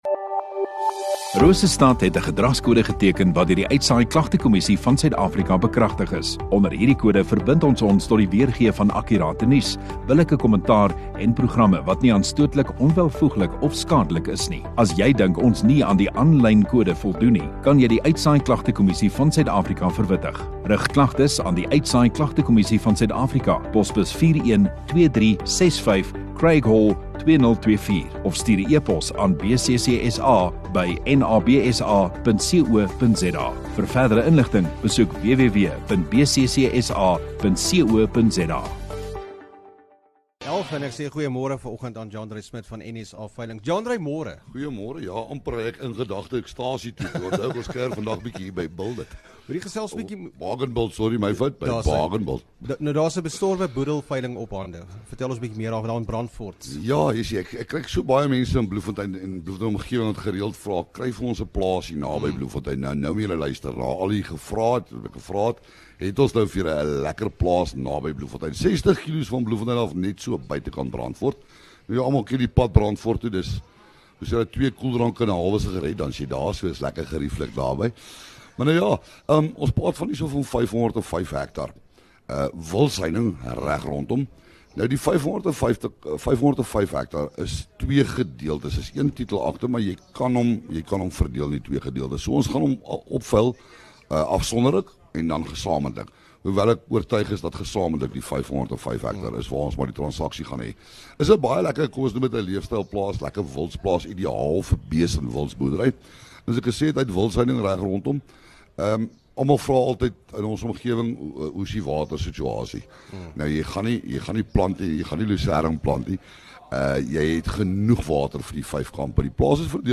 Radio Rosestad View Promo Continue Radio Rosestad Install Rosestad Onderhoude 21 Nov NSA veilings